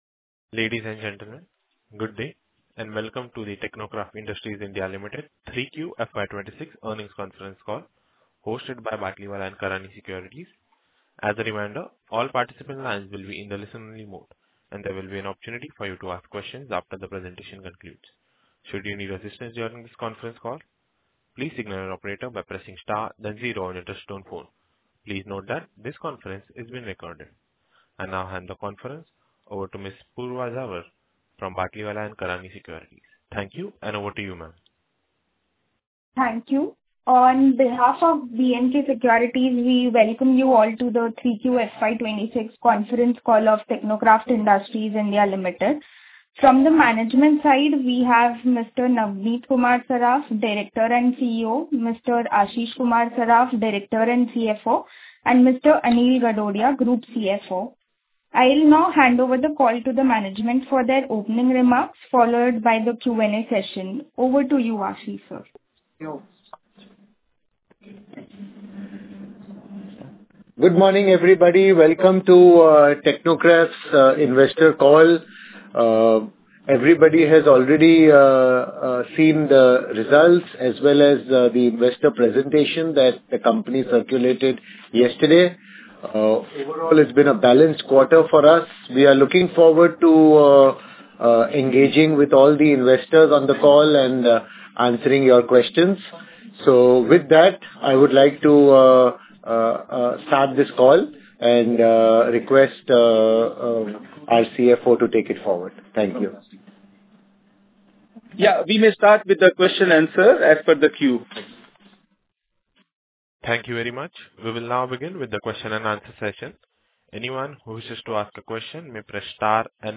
Concalls
Concall-Audio-FY26-Q3.mp3.mp3